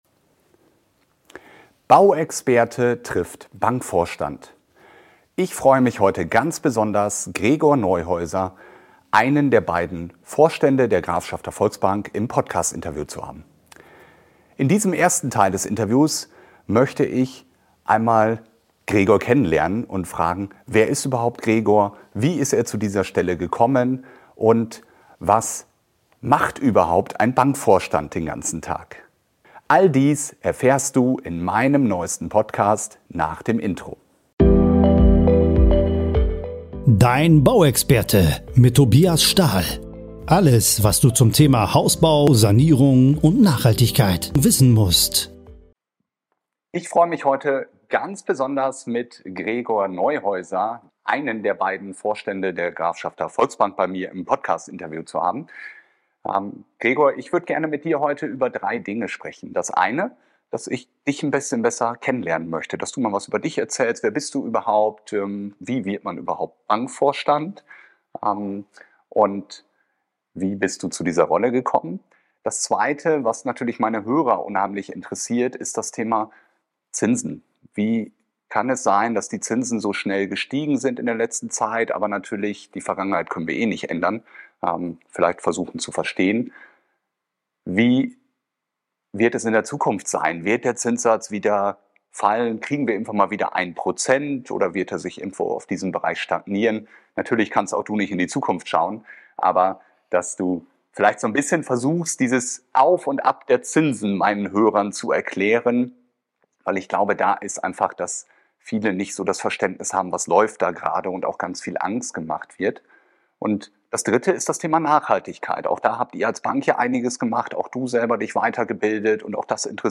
Bauexperte trifft Bankvorstand - Interview